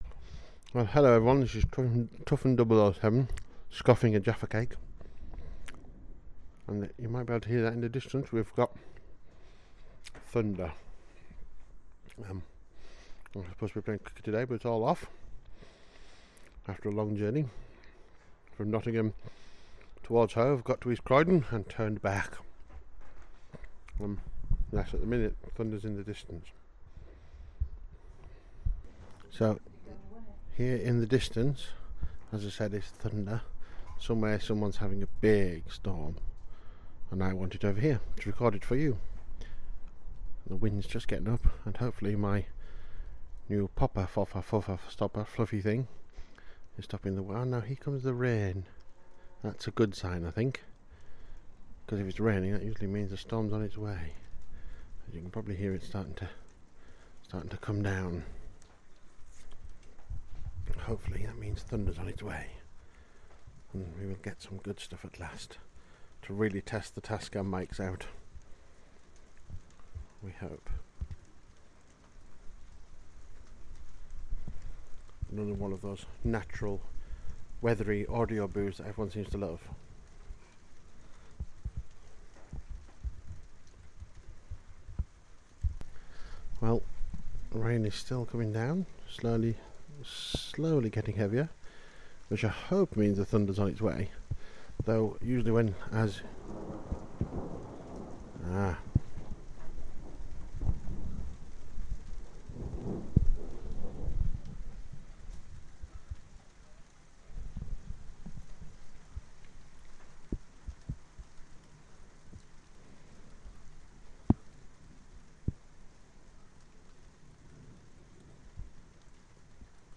Distant thunder part one